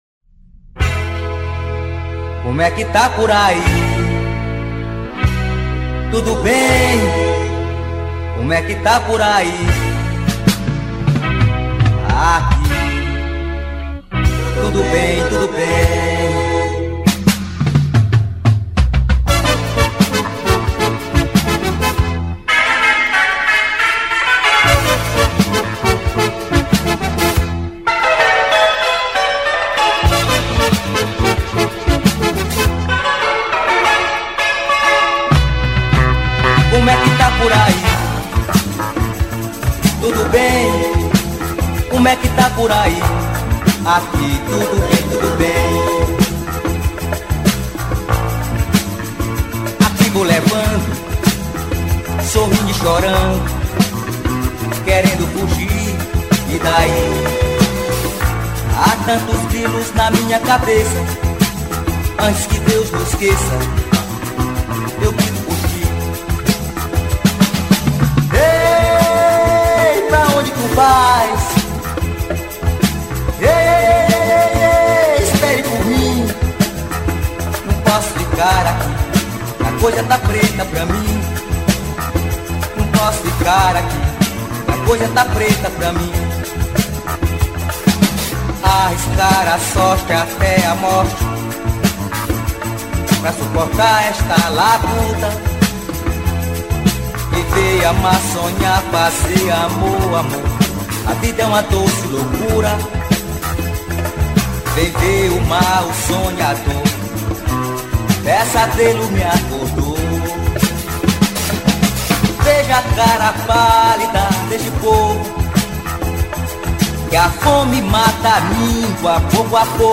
2637   04:09:00   Faixa: 10    Rock Nacional